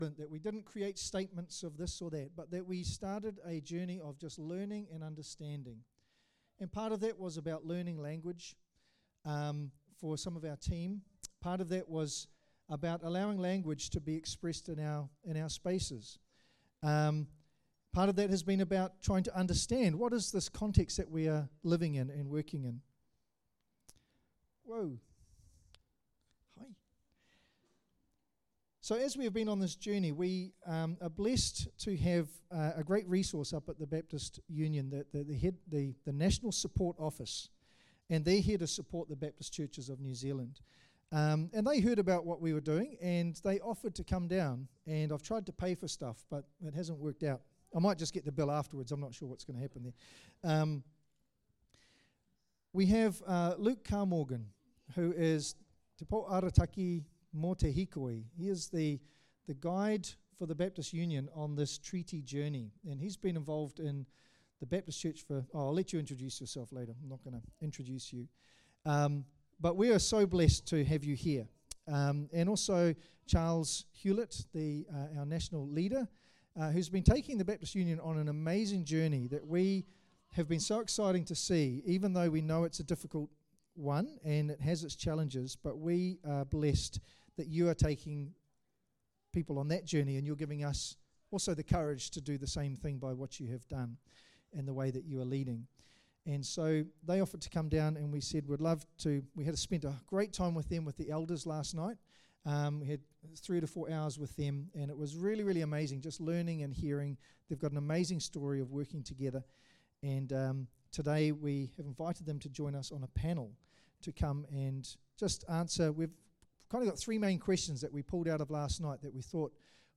A panel discussion